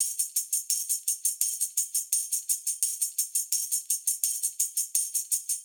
Index of /musicradar/sampled-funk-soul-samples/85bpm/Beats
SSF_TambProc2_85-01.wav